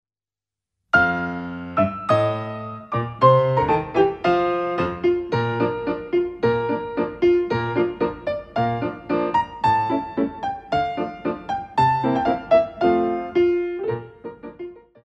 Ballet class music for children aged 5+
2T & 6/8